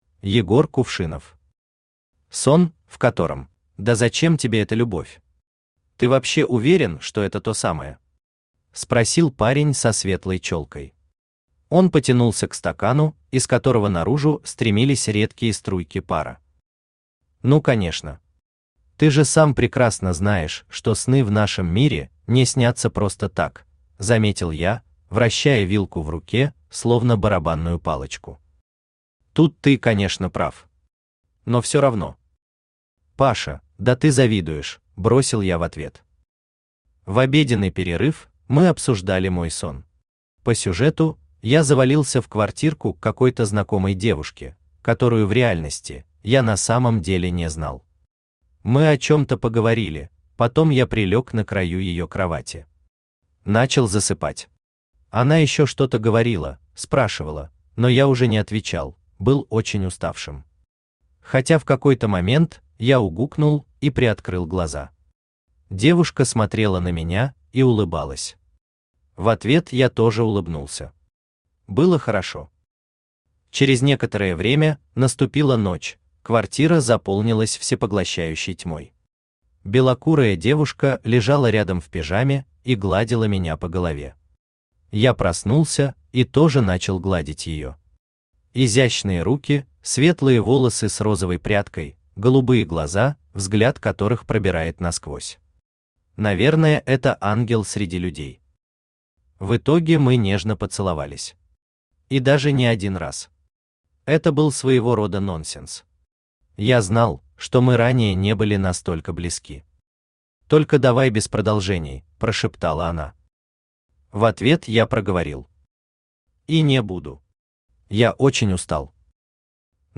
Аудиокнига Сон, в котором…